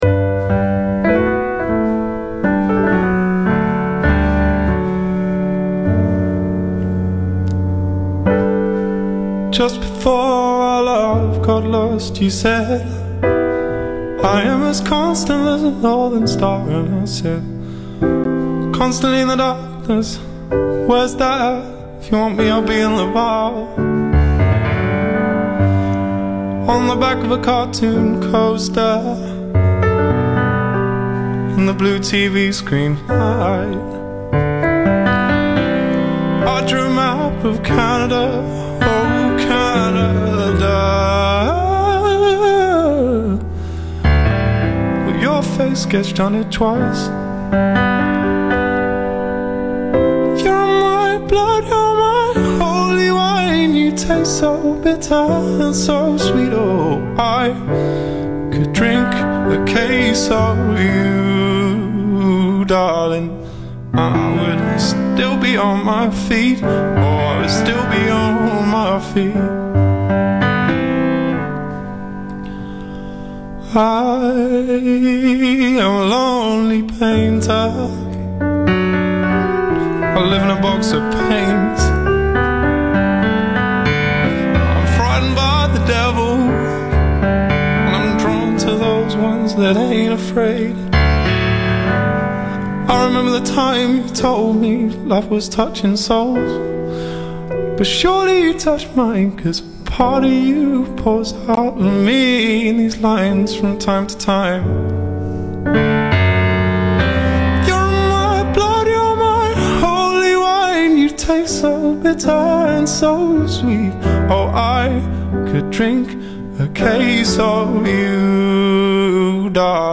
trop pleurnicharde et faussement émotive.